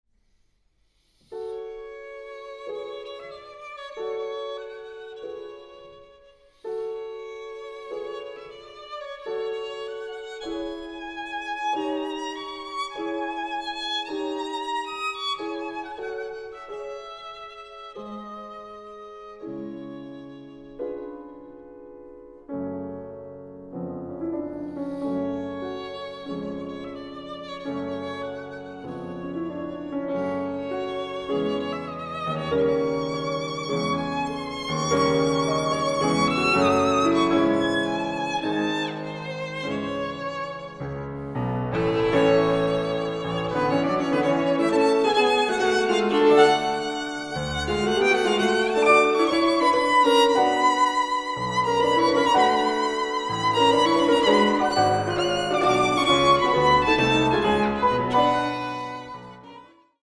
Sonatas for Piano and Violin
Piano
Violin